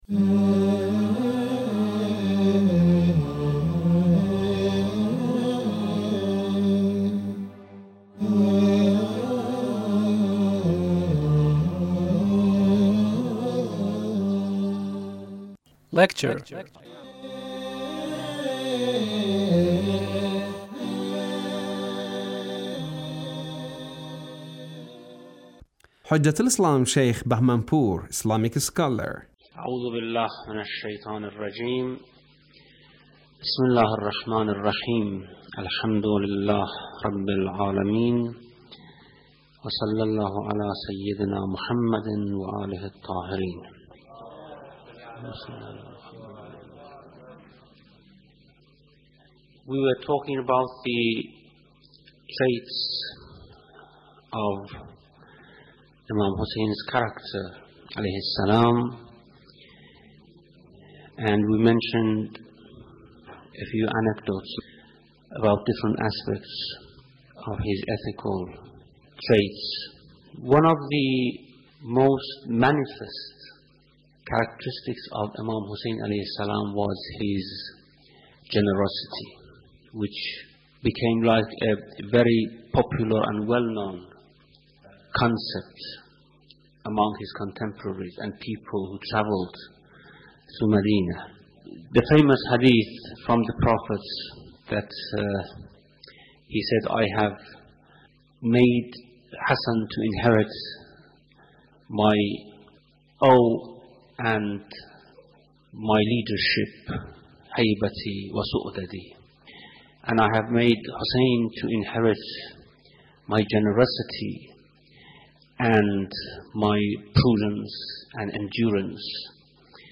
Lecture (4)